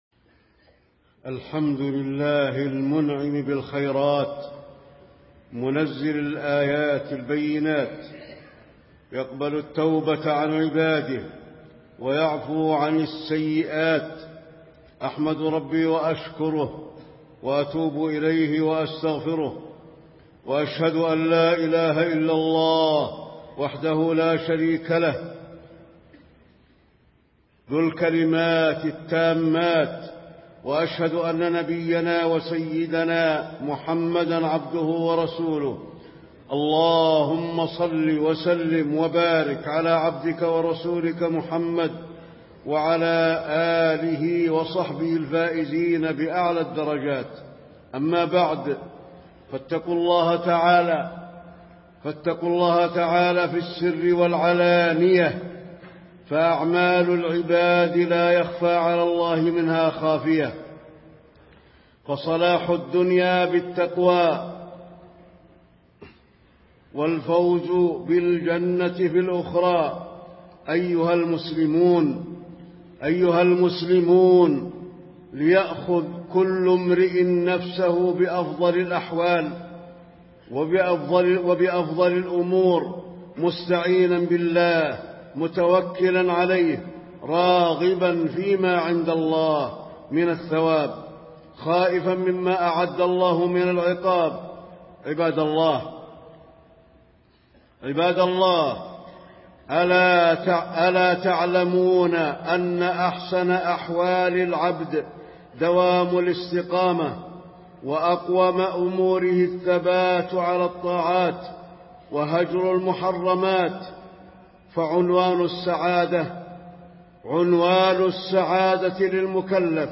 تاريخ النشر ١٣ ذو الحجة ١٤٣٤ هـ المكان: المسجد النبوي الشيخ: فضيلة الشيخ د. علي بن عبدالرحمن الحذيفي فضيلة الشيخ د. علي بن عبدالرحمن الحذيفي دوام الاستقامة والثبات على الطاعات